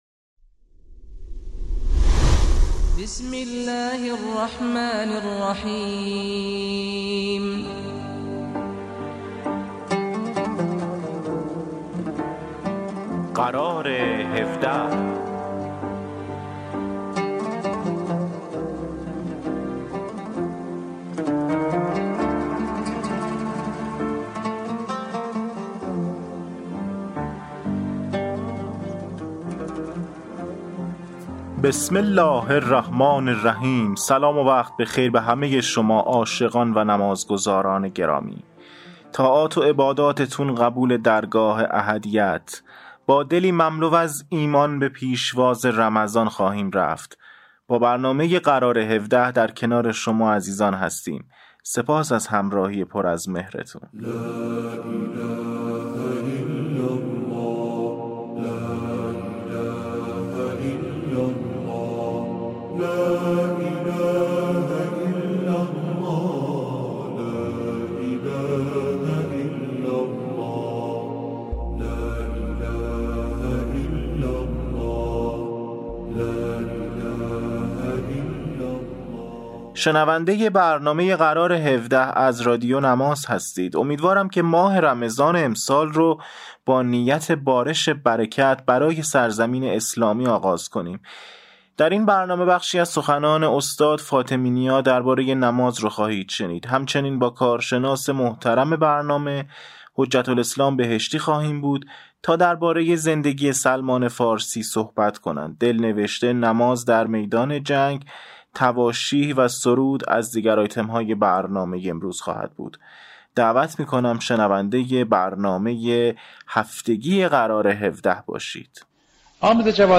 برنامه اینترنتی قرار هفده مجموعه ای از آیتم های نمازی و در مورد بخش های مختلف از نماز، دارای تواشیح، سخنرانی های نمازی، سرود و ترانه، دلنوشته، خاطرات و معرفی کتاب و … است.